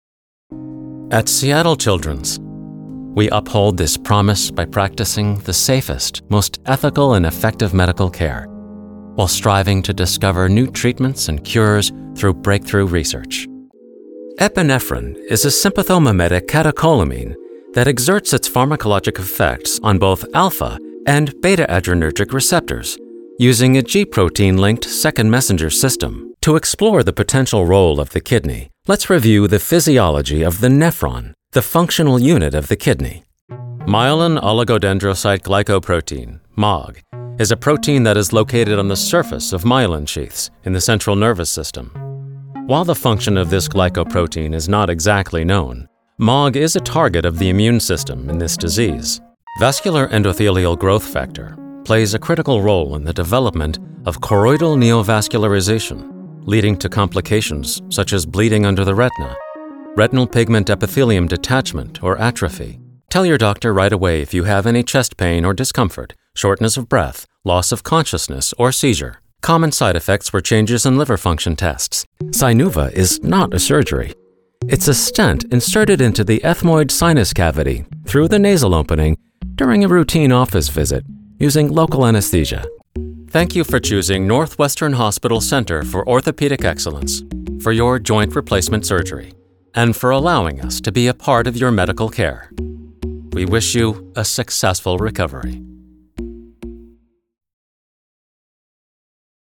Long-time actor, broadcast-ready voice overs, industry-standard studio with Source-Connect
Medical demo
English - USA and Canada
Young Adult
Middle Aged